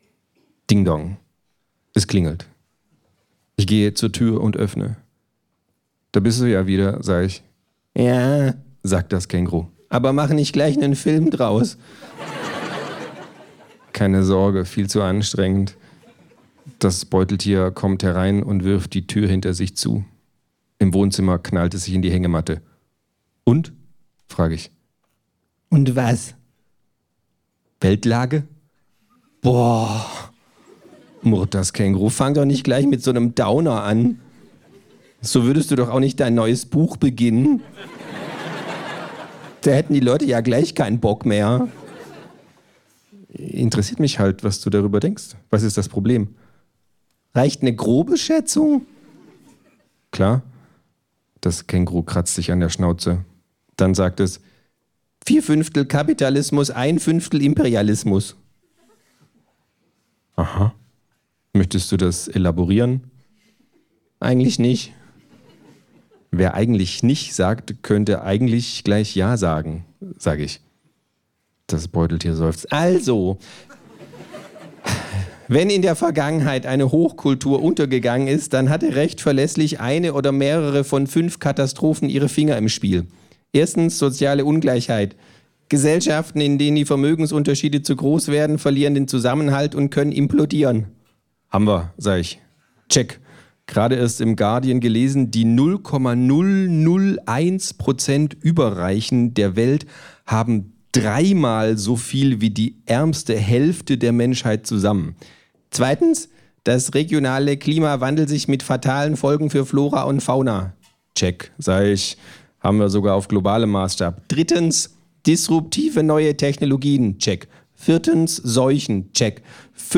Wie immer überragend eingesprochen von Marc-Uwe Kling persönlich ist auch das Hörbuch ein echter Genuss!
Sprecher Marc-Uwe Kling